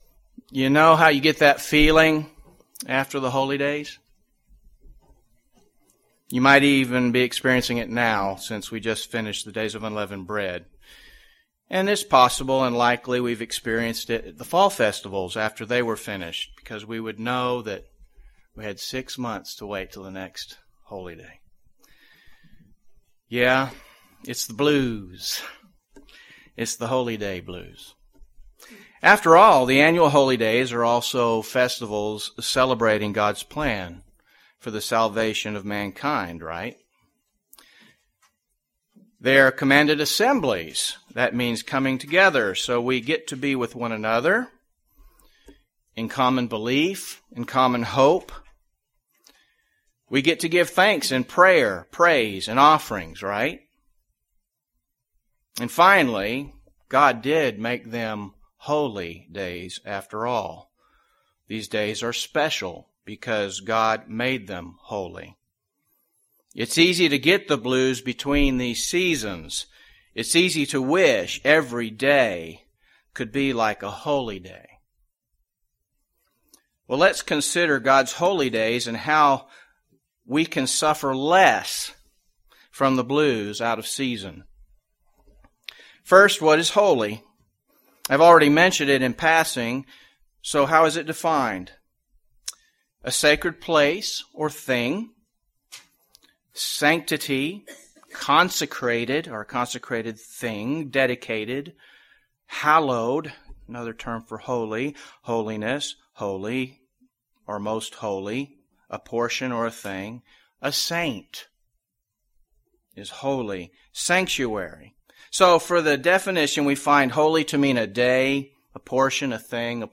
UCG Sermon True Holiness perspective Notes PRESENTER'S NOTES You know how you get that feeling after the Holy Days?